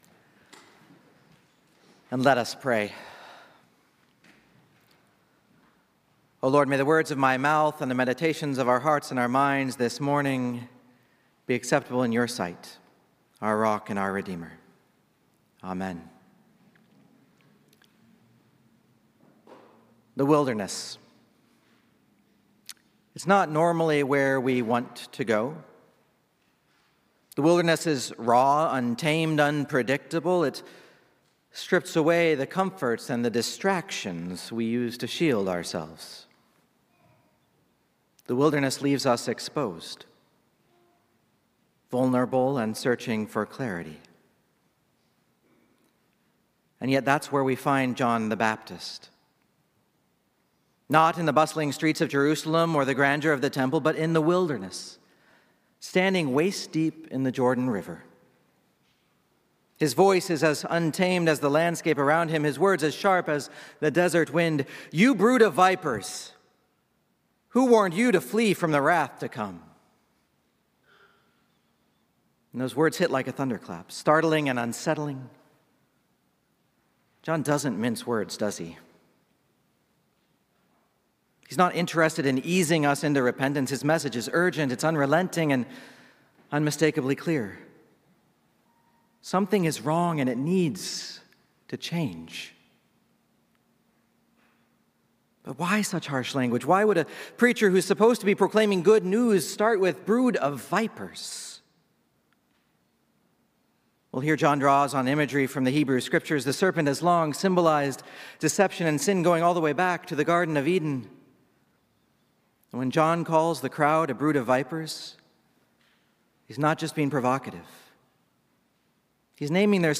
Sermons | Messiah Lutheran Church, Marquette